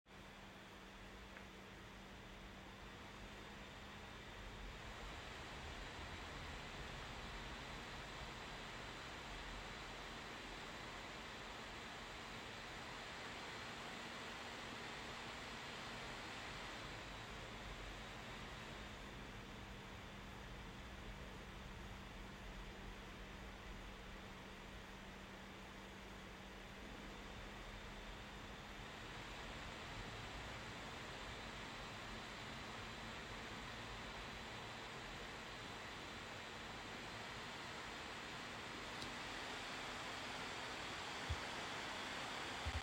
Im Profil „Leistung“ ist das Kühlsystem hingegen dauerhaft wahrnehmbar und reagiert nervös auf kurze Lastspitzen.
Den Unterschied macht, wie langsam dieser Wechsel vonstatten geht: Im höchsten Profil wechselt das GeekBook quasi ununterbrochen zwischen „laut“ und „leise“, im kleinsten verharrt es länger an den beiden Extremen.
Geekom GeekBook X14 Pro: Kühlsystem im Modus Leistung (CB 2026 MC)